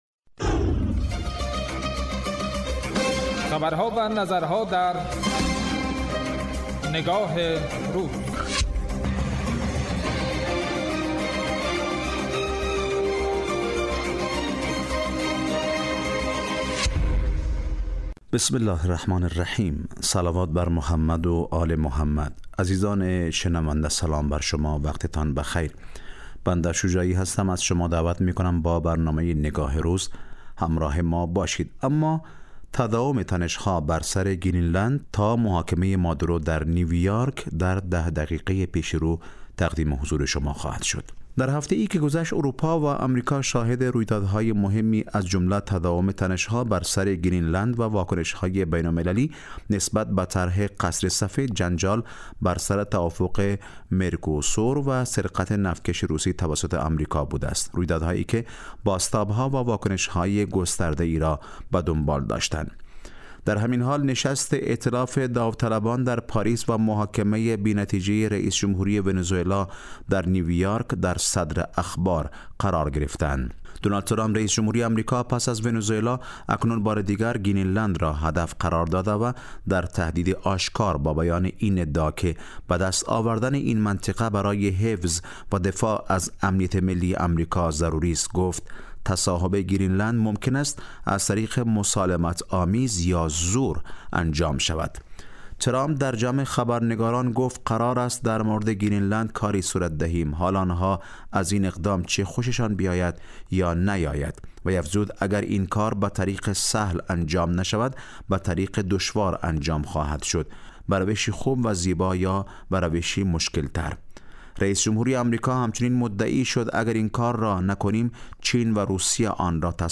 برنامه تحلیلی